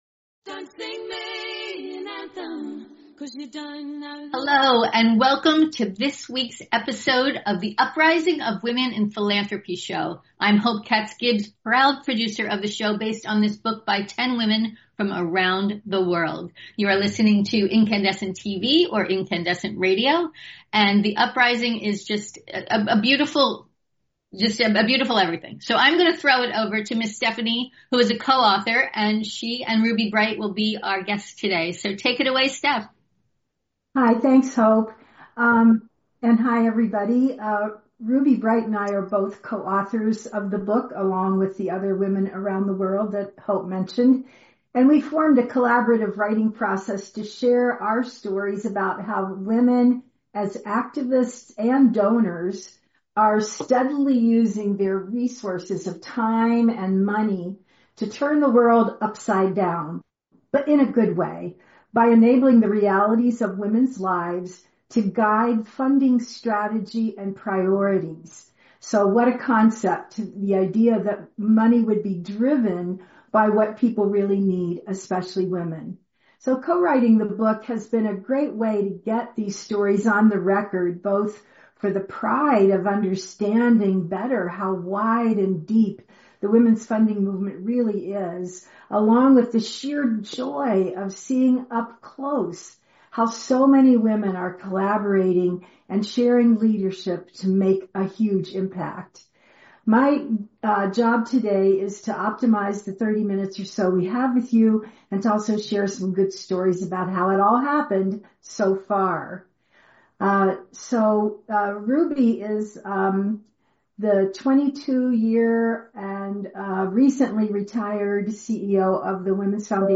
Today’s podcast topic: The True Story of How The Realities of Women’s Lives Turned A Global Financial System Upside Down In today’s interview you’ll meet